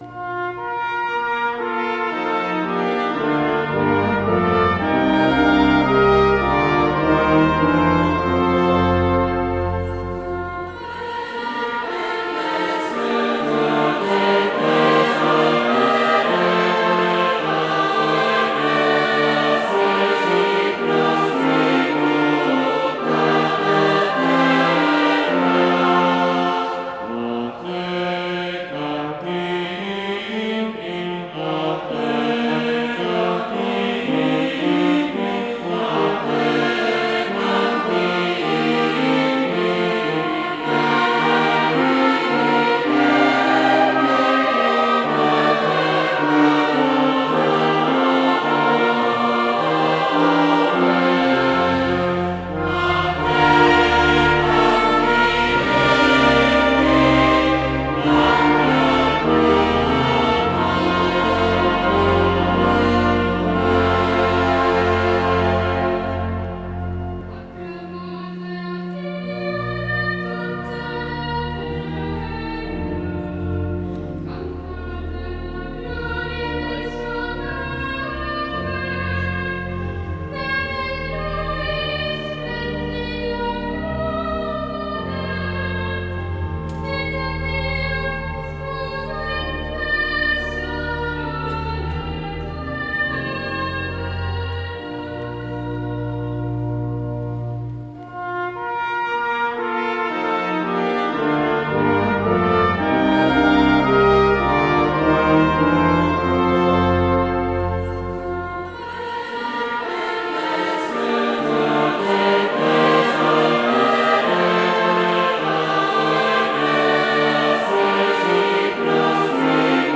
Gallery >> Audio >> Audio2016 >> BeatificazioneSantocanale >> 13-CantoOffertorio Beatif12giu2016
13-CantoOffertorio Beatif12giu2016